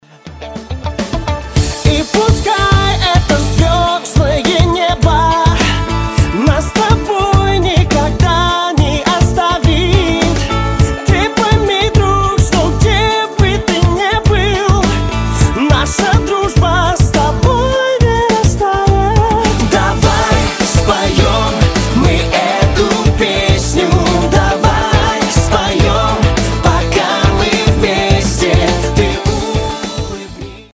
• Качество: 256, Stereo
поп